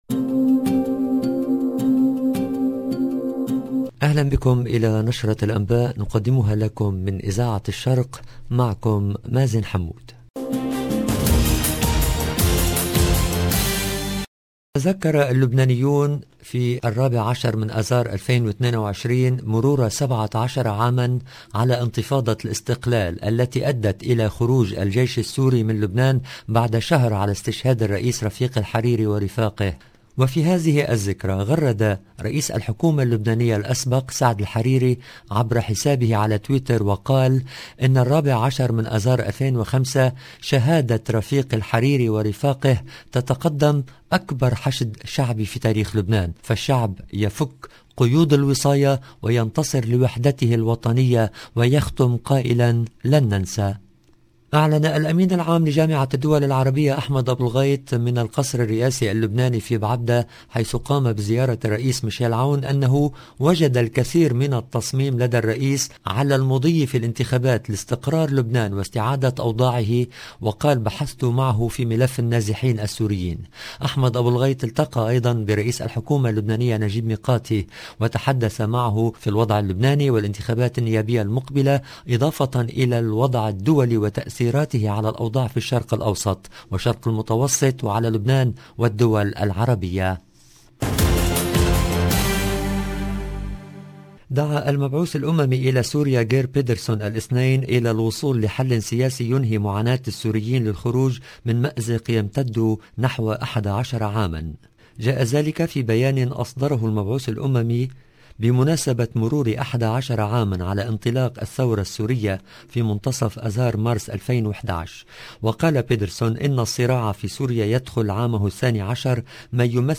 LE JOURNAL EN LANGUE ARABE DU SOIR DU 14/03/22